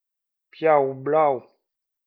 Pour bien prononcer :
Occitan limousin : Piaus blaus